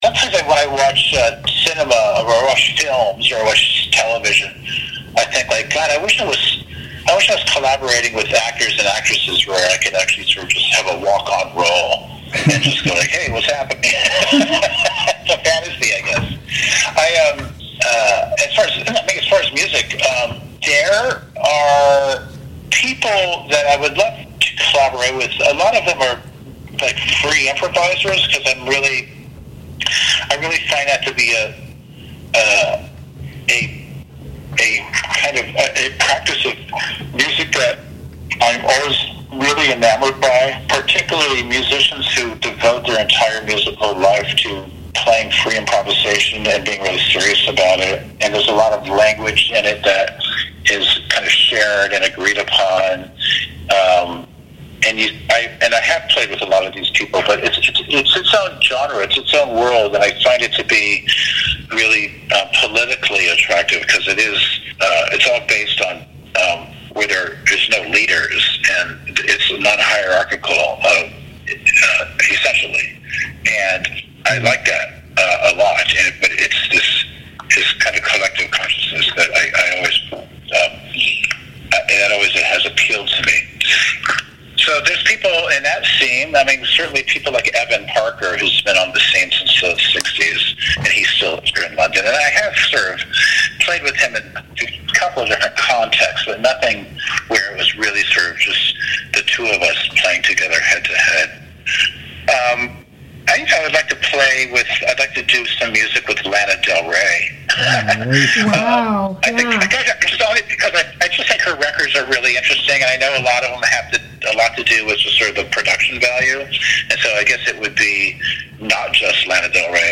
Thurston Moore Interview on WTSQ (Part 2 of 2)